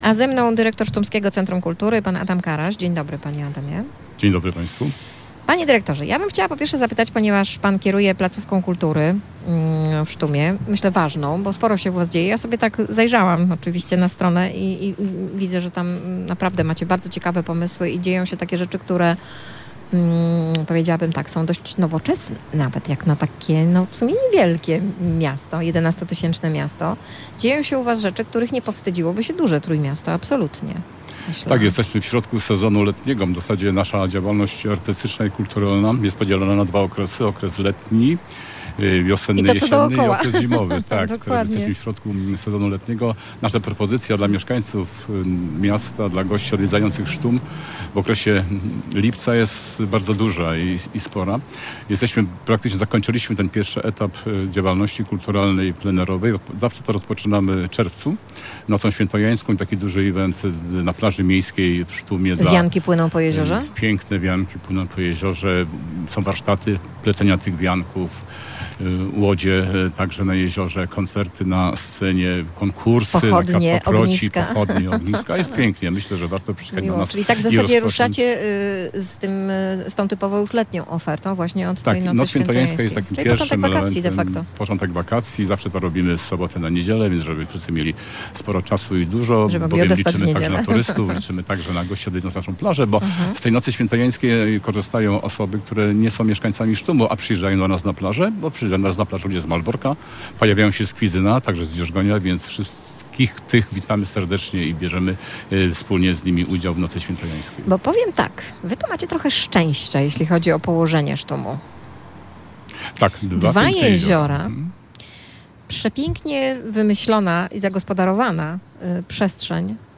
Taką już mamy zasadę, że letnie soboty spędzamy w niezwykłych miejscach naszego regionu.